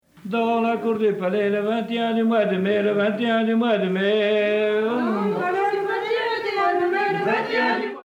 Origine : Bretagne (pays de Ploërmel)
solo puis chœur
Enregistré en 1977